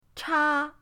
cha1.mp3